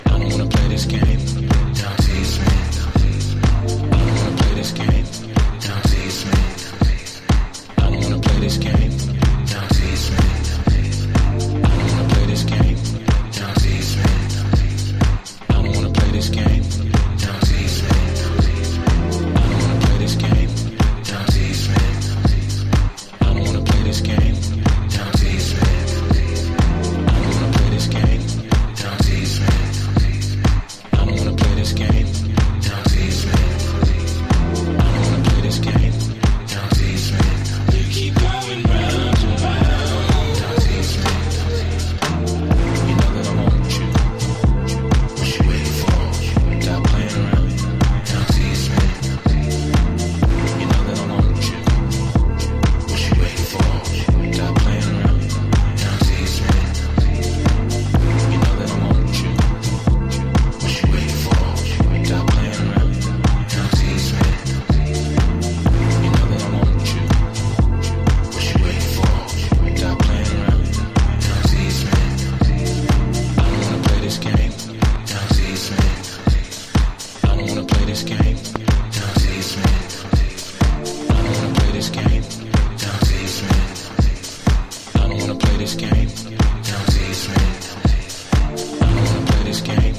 DEEP HOUSE / EARLY HOUSE